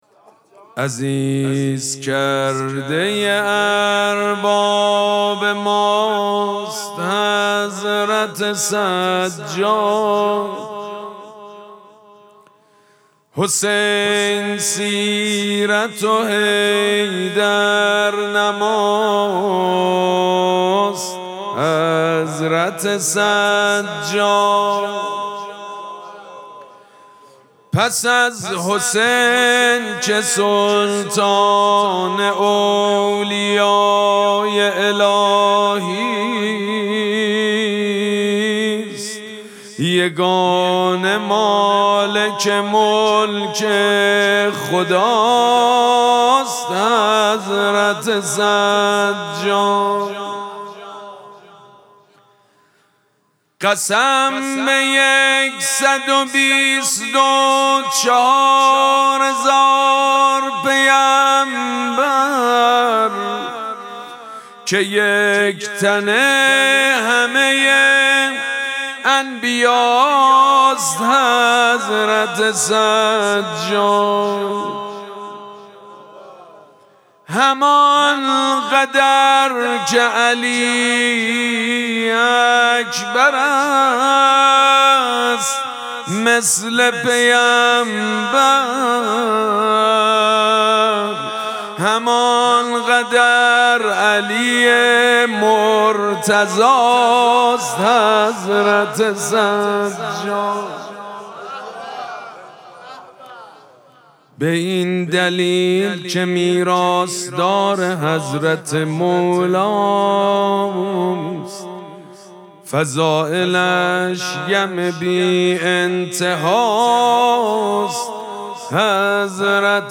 مراسم مناجات شب چهارم ماه مبارک رمضان
حسینیه ریحانه الحسین سلام الله علیها
مدح
مداح
حاج سید مجید بنی فاطمه